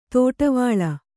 ♪ tōṭavāḷa